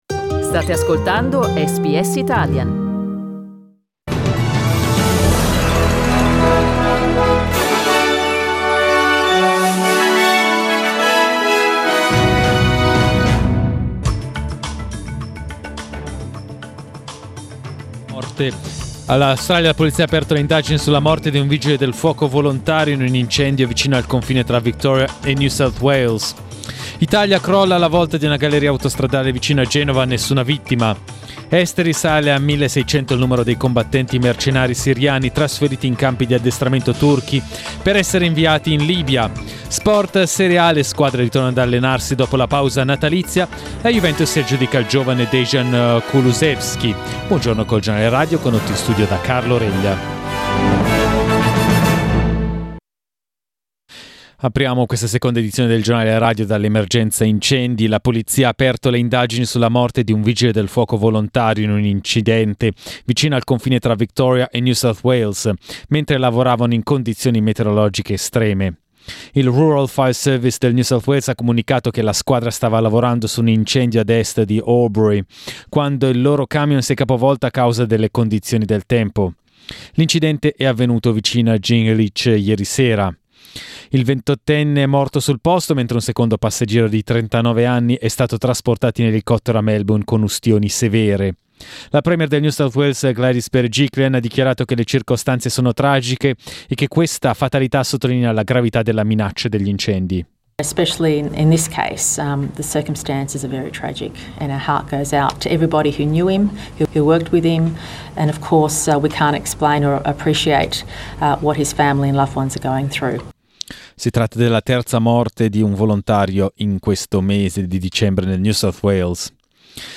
Our news bulletin (in Italian).
Giornale radio martedì 31 dicembre Source: Pexels